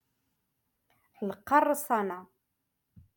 Moroccan Dialect-Rotation Five-Lesson Sixty Two